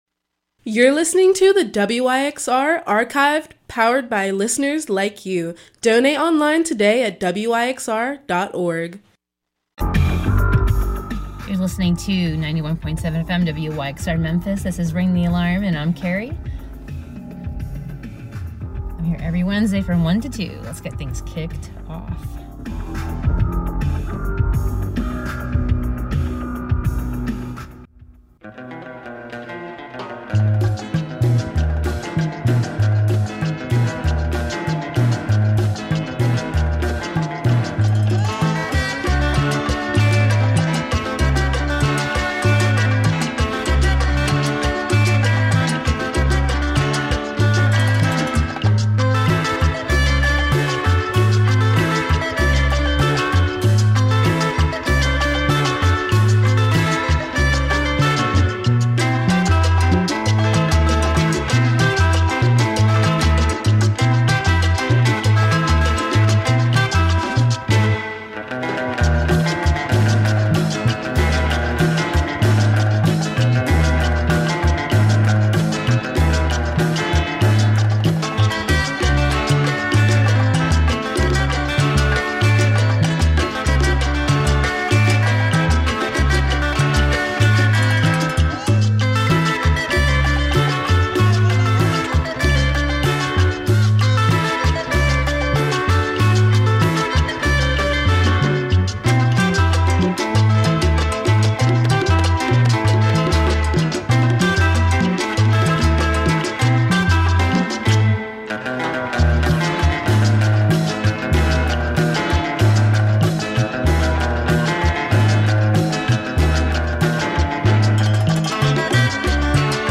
World Dance